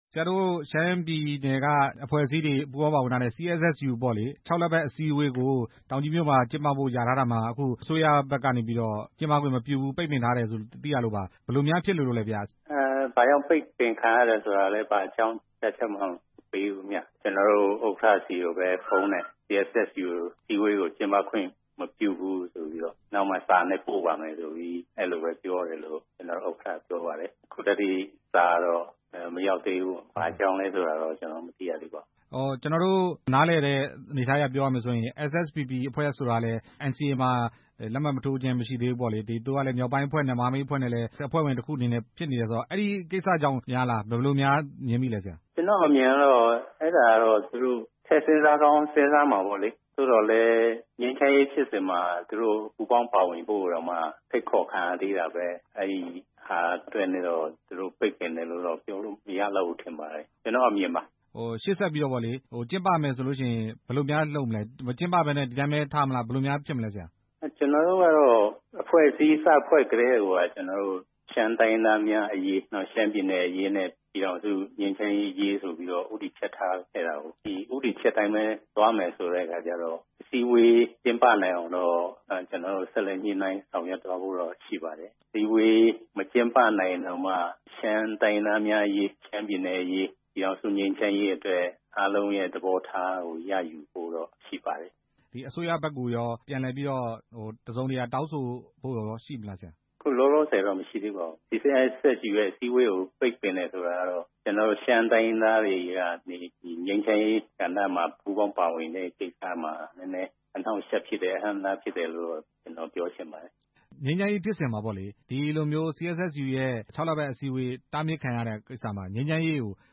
CSSU အစည်းအဝေး ယာယီရွှေ့ဆိုင်းတဲ့အကြောင်း မေးမြန်းချက်